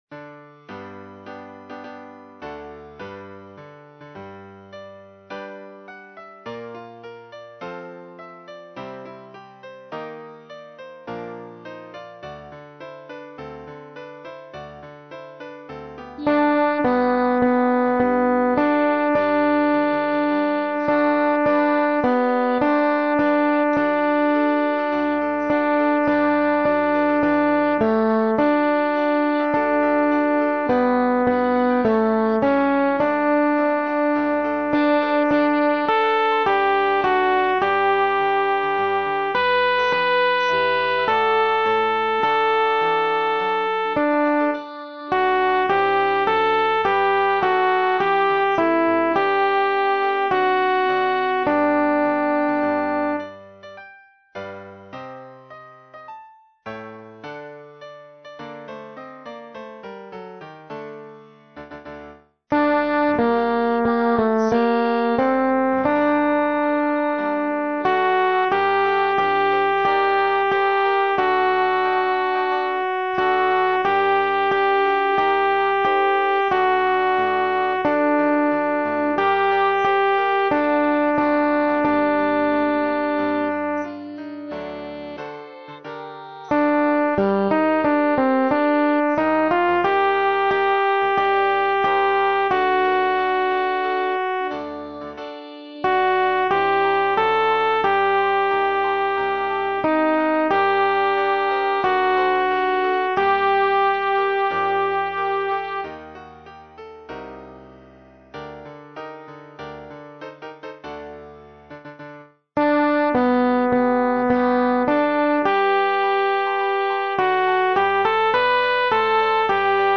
アルト1（歌詞付き）